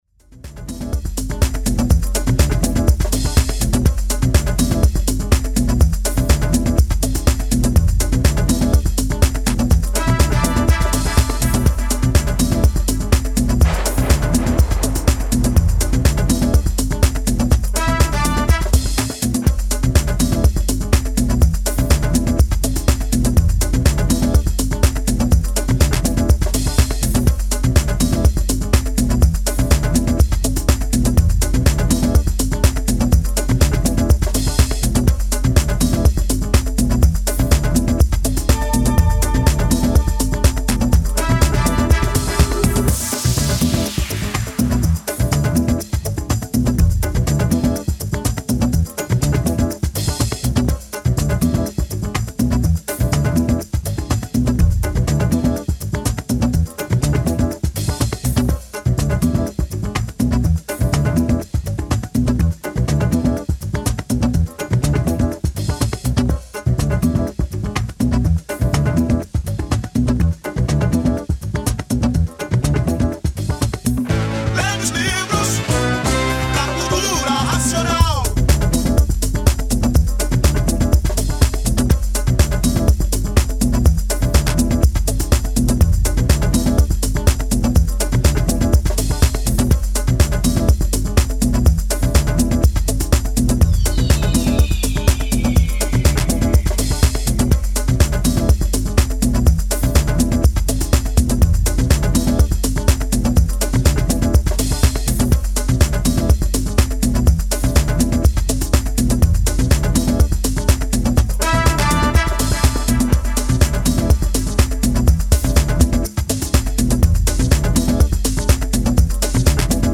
pure brasilian flavoured piece of funky house music
Style: House